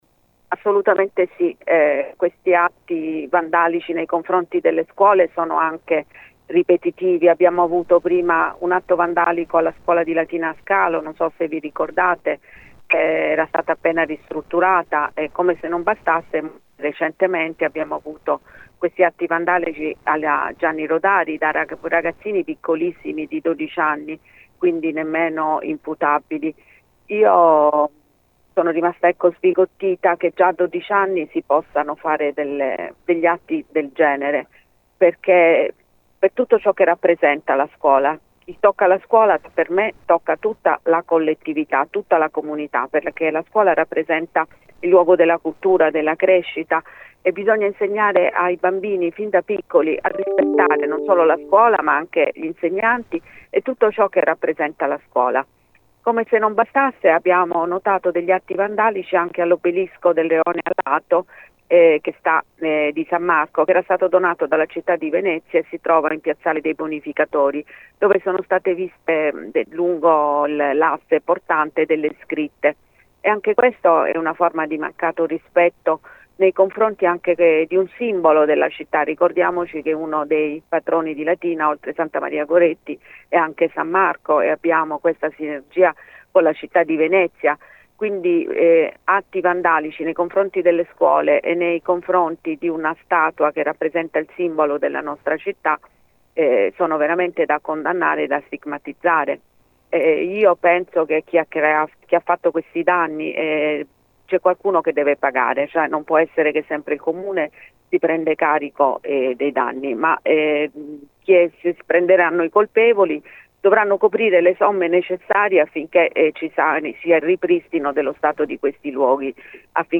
Lne abbiamo parlato con lei per Gr Latina